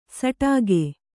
♪ saṭāge